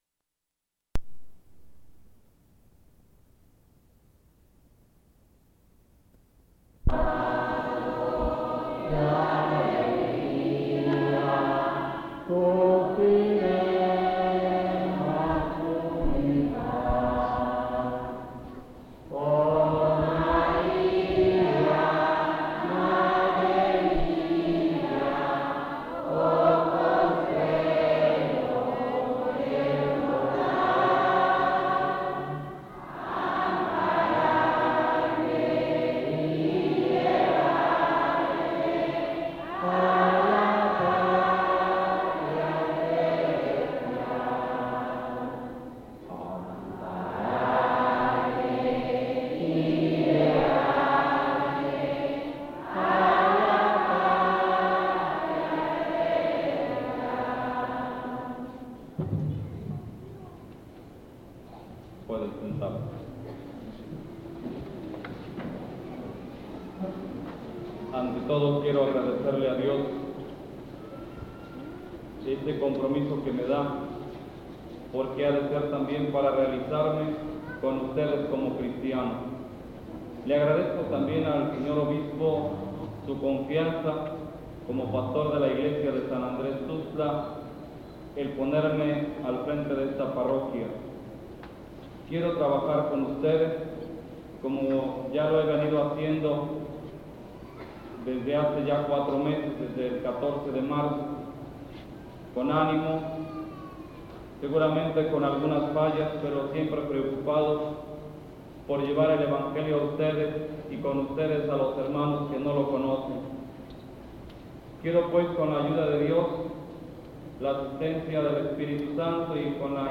01 Misa oficiada por el obispo
Santiago Tuxtla, Veracruz
Ceremonia religiosa
Trompeta Guitarra Guitarron Vihuela Violín
Fiesta del Señor Santiago